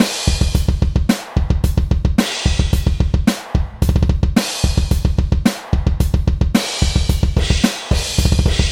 Tag: 110 bpm Heavy Metal Loops Drum Loops 1.47 MB wav Key : Unknown